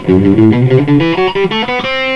Ok, here is the tab, just a 12 note ascending run in E Natural Minor from the F note.
Alternative D-U-D-D-U-D-D-U-D-D-U-D   When going D-D or U-U, just drag your pick and sweep across the strings